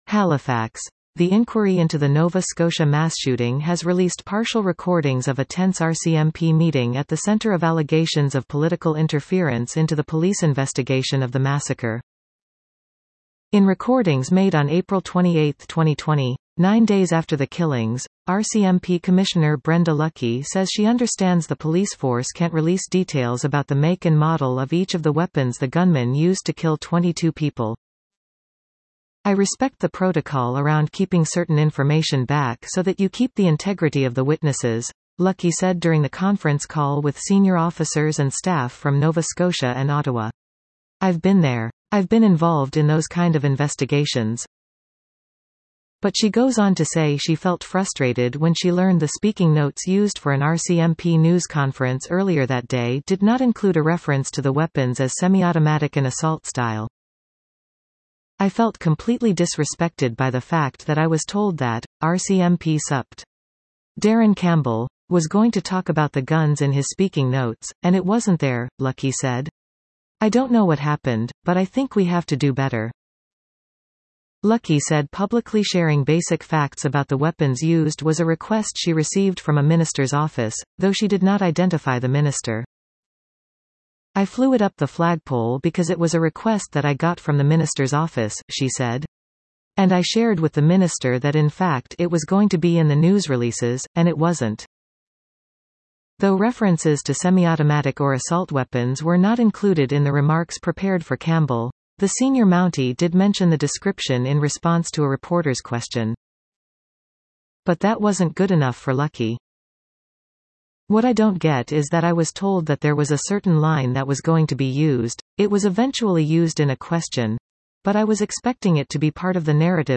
The inquiry has released partial recordings of a tense RCMP meeting that is at the centre of allegations of political interference into the police investigation of the massacre